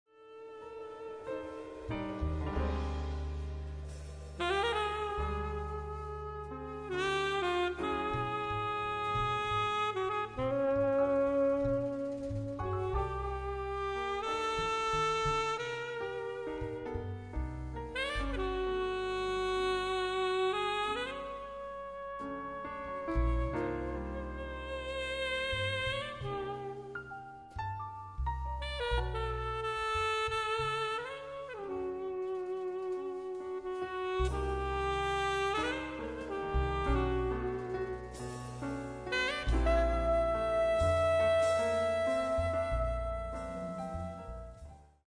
piano
sax
bass
drums
percussion